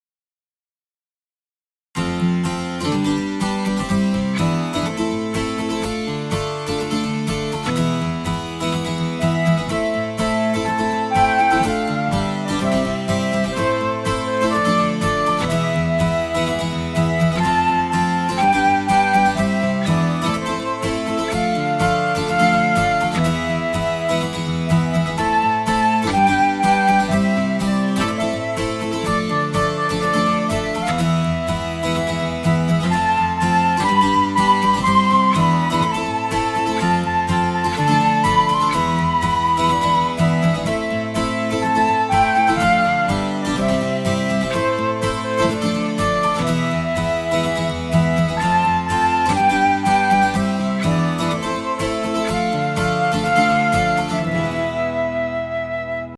InstrumentalCOUPLET/REFRAIN